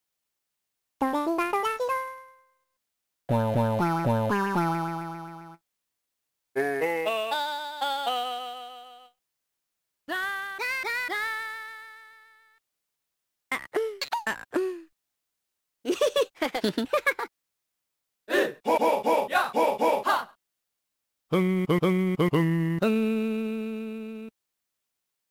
Voice premade demonstrations in WarioWare: D.I.Y.